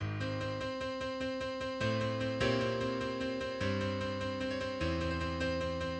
У пісні «Лісовий цар» Ф. Шуберта використаний малий нонакорд (C7 9) особливо ефективний для посилення драматизму та відчуття загрози.
(Уривок з балади «Лісовий цар» Шуберта – посилання на уривок [Архівовано 20 листопада 2021 у Wayback Machine.])